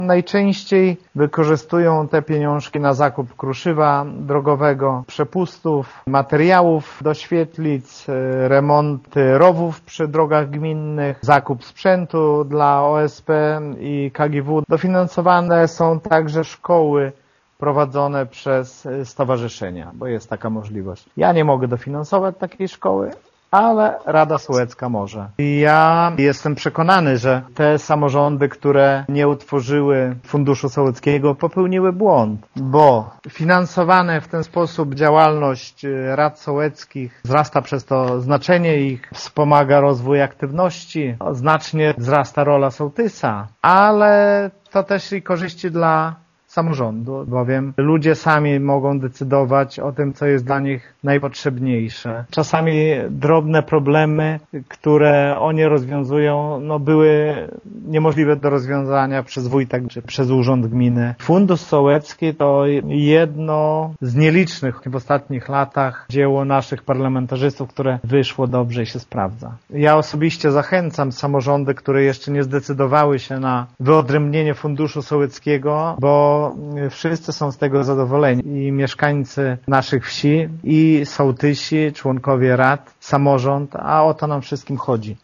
„W 2010 roku było to niecałe 290 tysięcy złotych, a w tym roku - 315 tysięcy” - mówi Lech Szopiński: